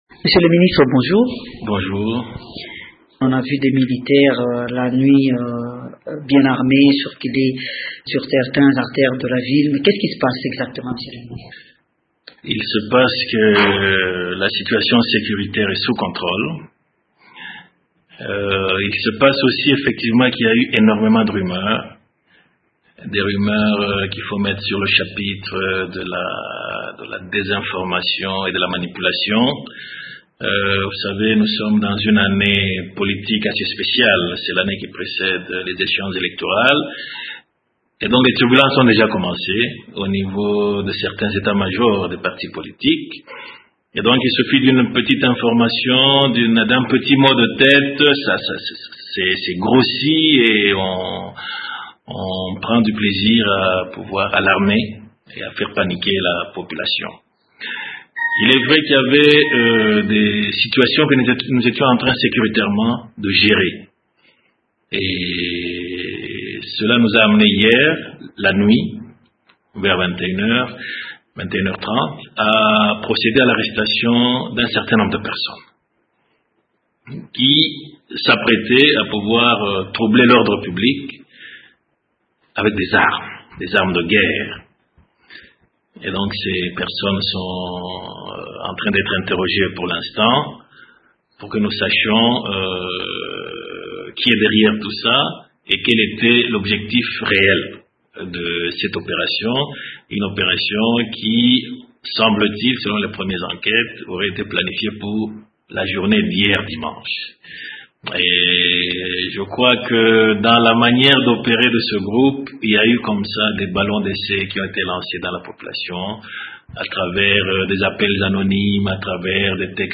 Le ministre provincial de l’Intérieur et de la Communication du Katanga répond.